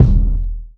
Medicated Kick 10.wav